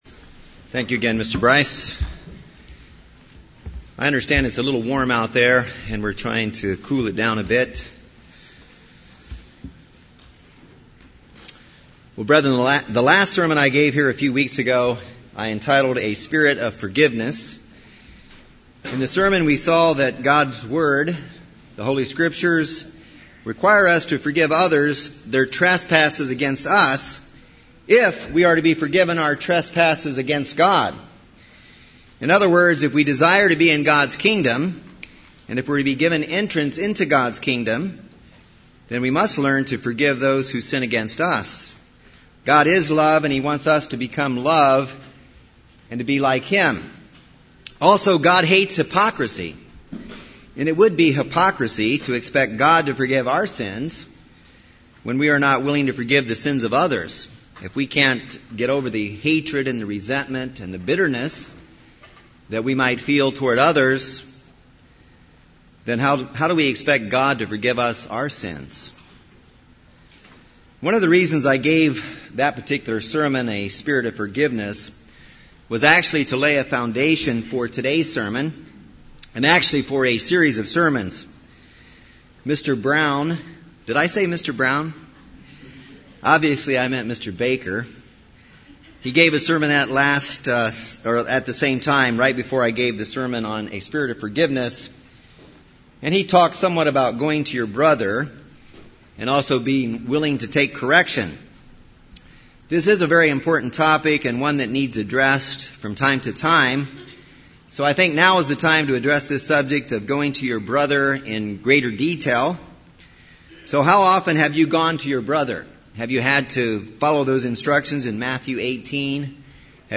This sermon addresses what the Bible means by going to your brother and answers the questions of why, how, and when to go to your brother.